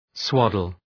Shkrimi fonetik {‘swɒdəl}